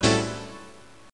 chord.wav